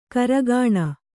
♪ karagāṇa